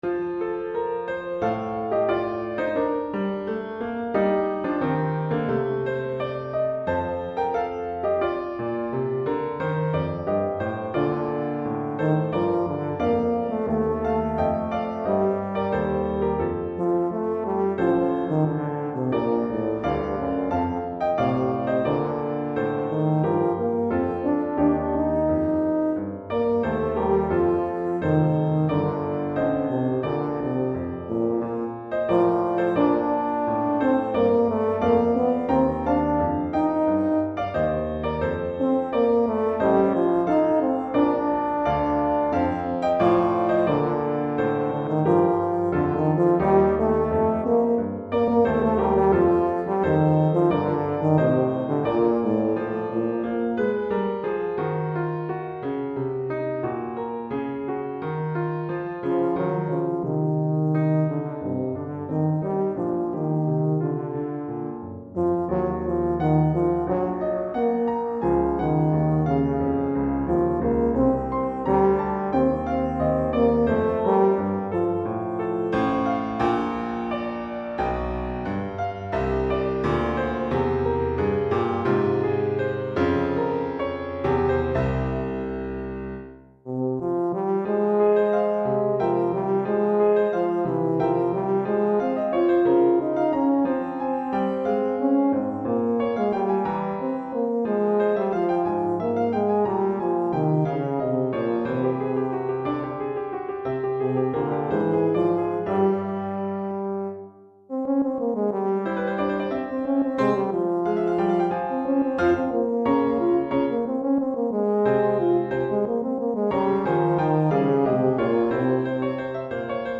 Répertoire pour Tuba, euphonium ou saxhorn